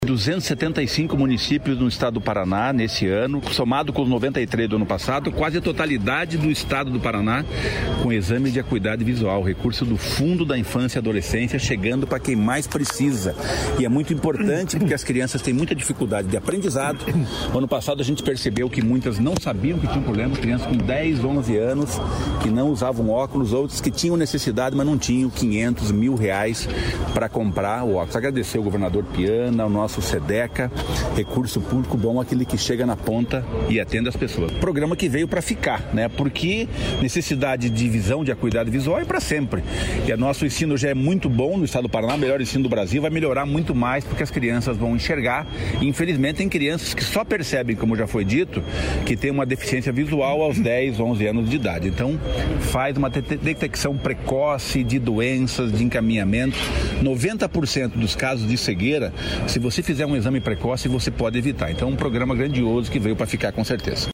Sonora do secretário do Desenvolvimento Social e Família, Rogério Carboni, sobre o programa Bons Olhos